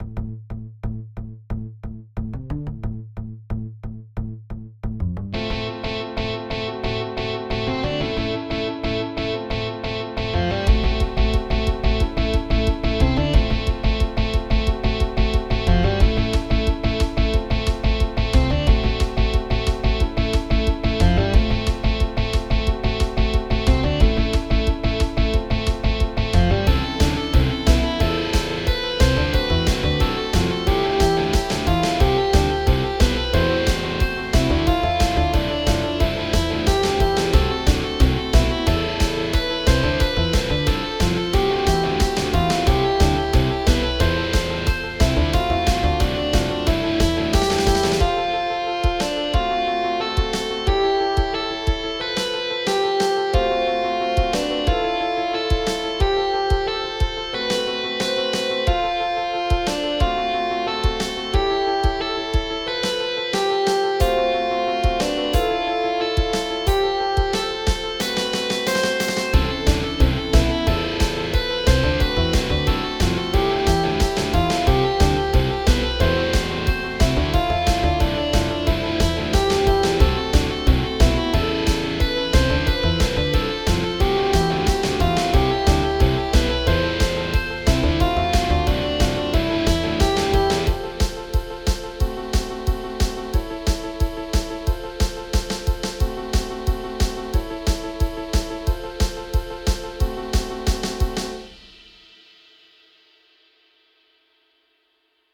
戦闘用で使うことを想定しています。
ザコ敵戦用に作ったけど、中ボス戦でも使えそう。